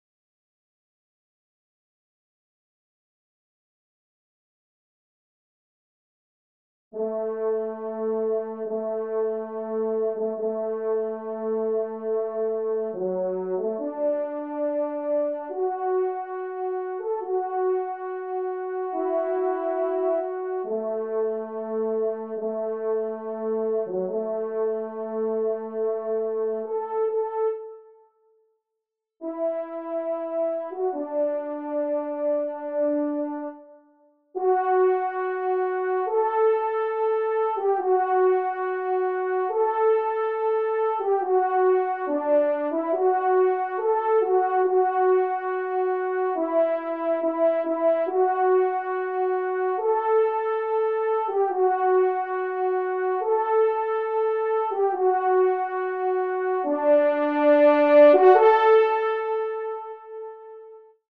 1ère Trompe